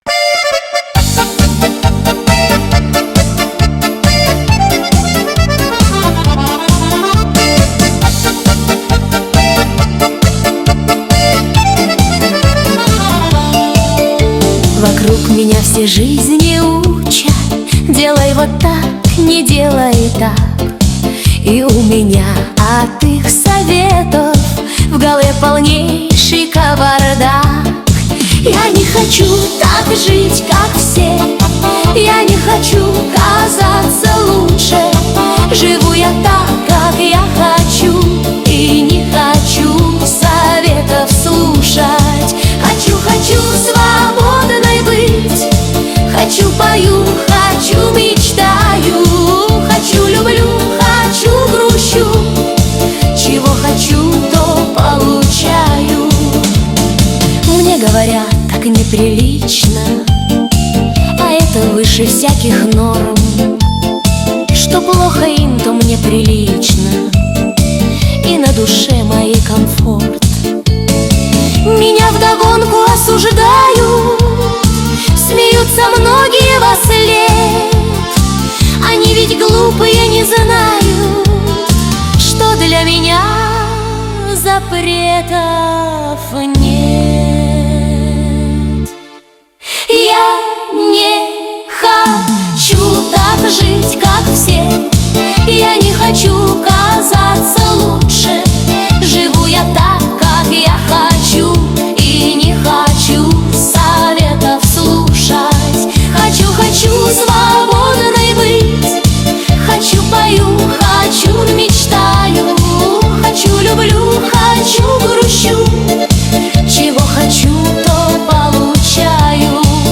Качество: 266 kbps, stereo
Нейросеть Песни 2025, Стихи